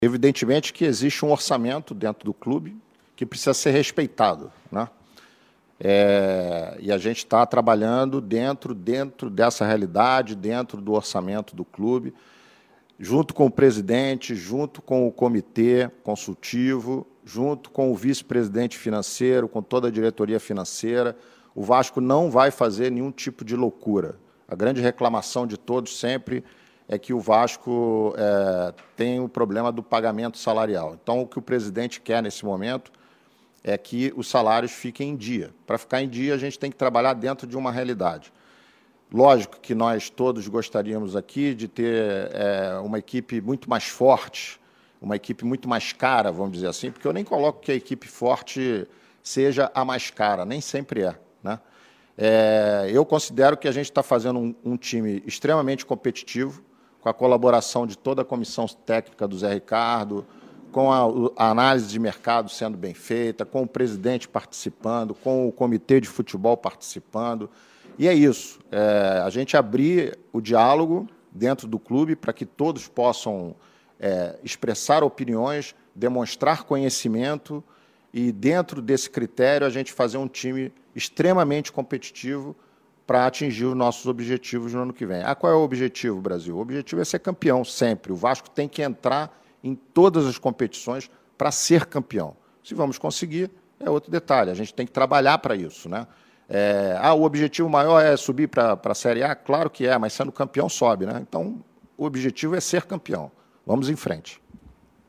entrevista coletiva